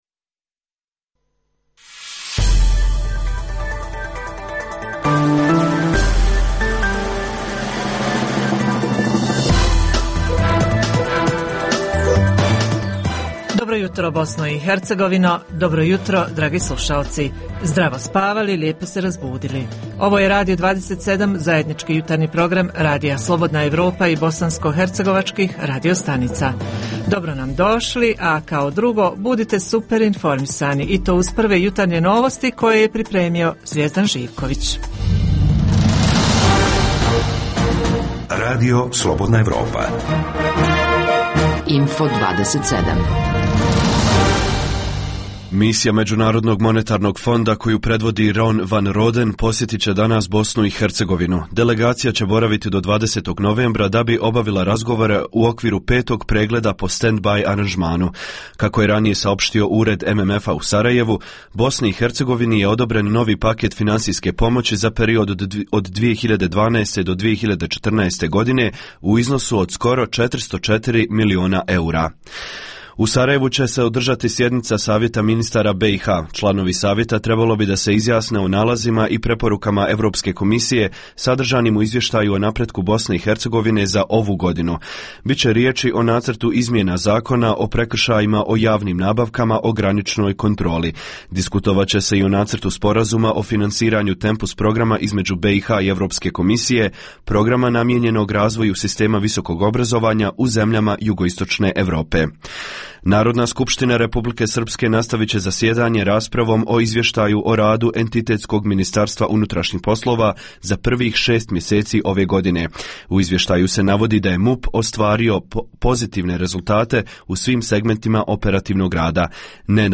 Uz tri emisije vijesti te podsjećanje na prve rezultate popisa stanovnika u BiH, slušaoci mogu uživati i u ugodnoj muzici.